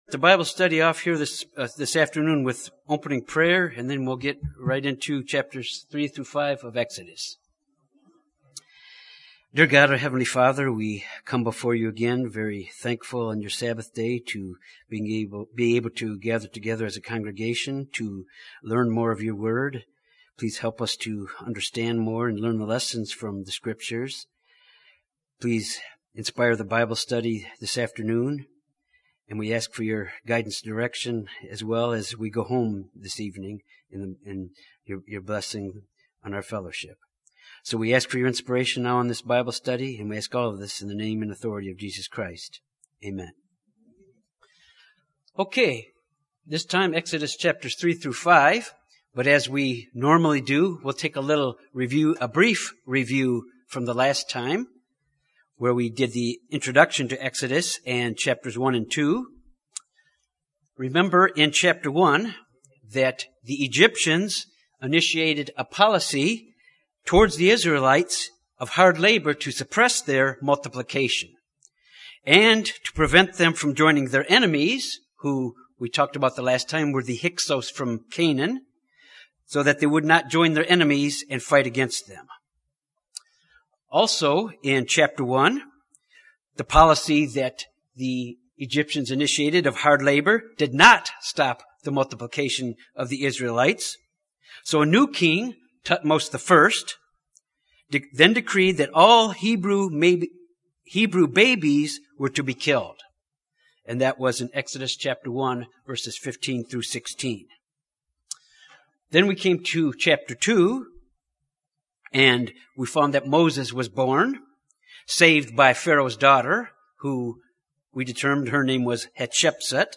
Given in Little Rock, AR Jonesboro, AR Memphis, TN